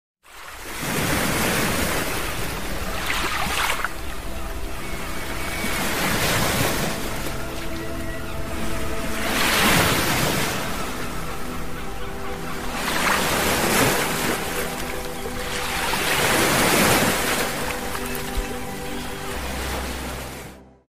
A quiet night on the sound effects free download
A quiet night on the beach, where the soft waves kiss the shore, and a warm lantern glows under the endless galaxy.